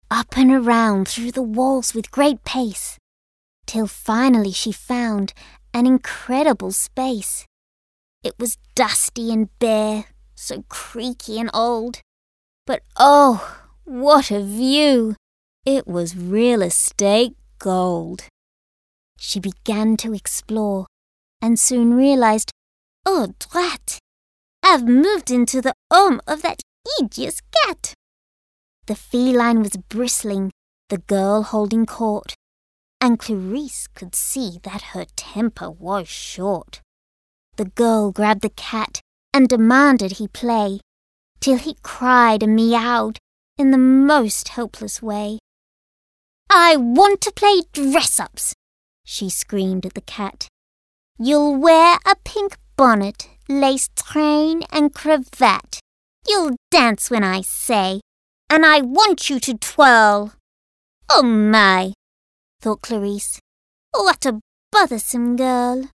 Female
English (Australian)
It is childlike, quirky and otherworldly.
I am very emotive and have a natural theatrical flair.
Audiobooks